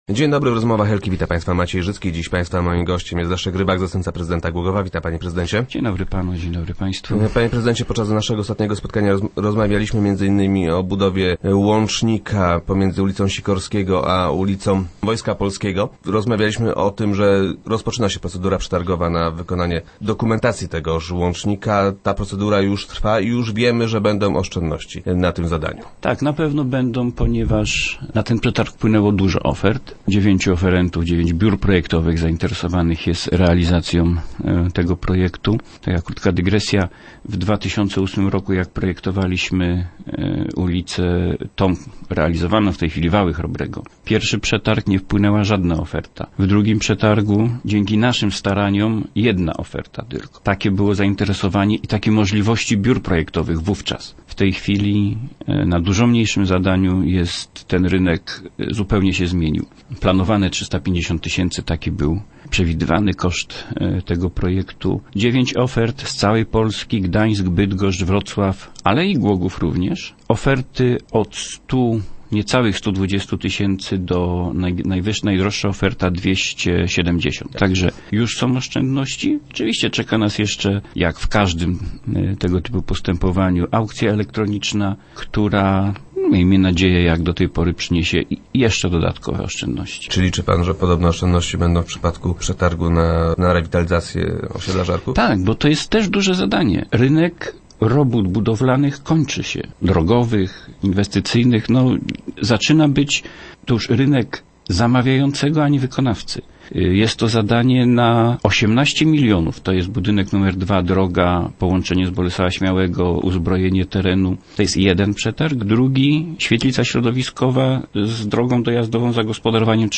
- Wszystko wskazuje na to, że zapłacimy mniej niż planowaliśmy - mówi Leszek Rybak, zastępca prezydenta Głogowa.